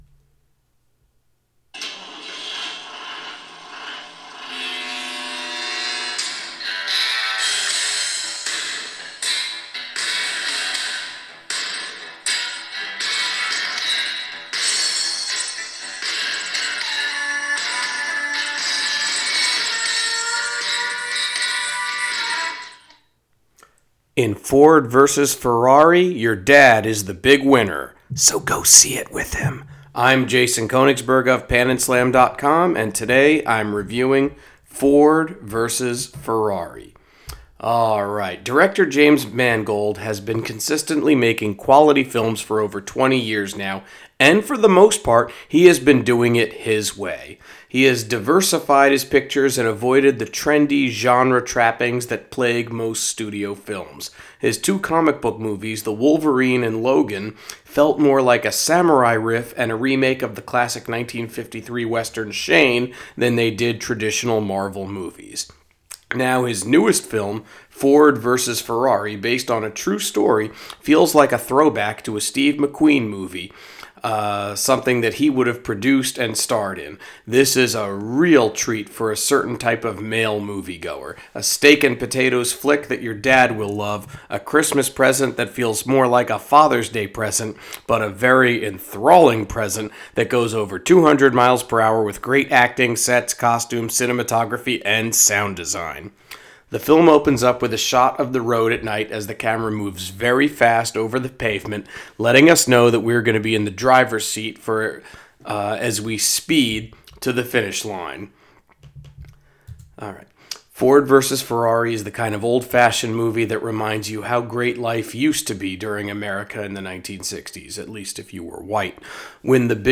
Movie Review: Ford v Ferrari